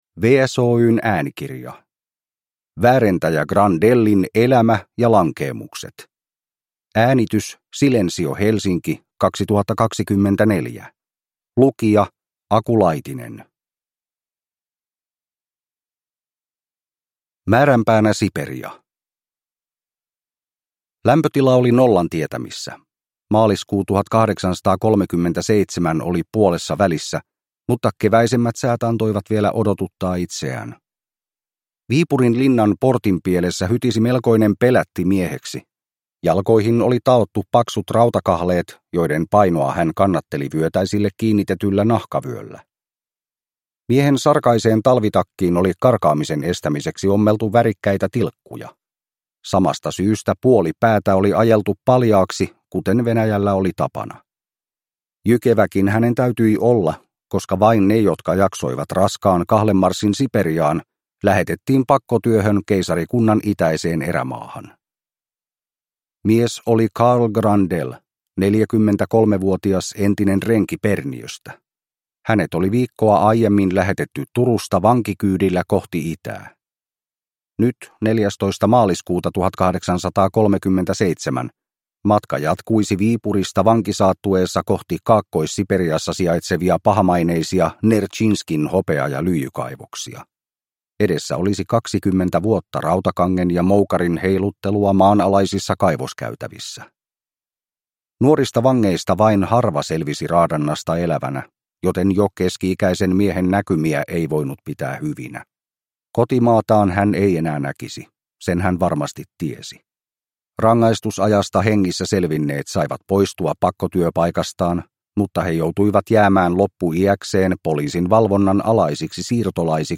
Wäärentäjä Grandellin elämä ja lankeemukset – Ljudbok
• Ljudbok